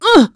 Isolet-Vox_Damage_01.wav